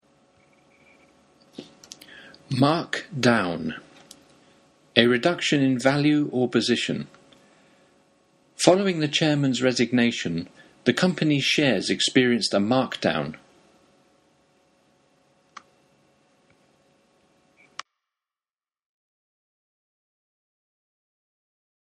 マンツーマン英会話レッスンの担当の英語ネイティブによる発音は下記のリンクをクリックしてください。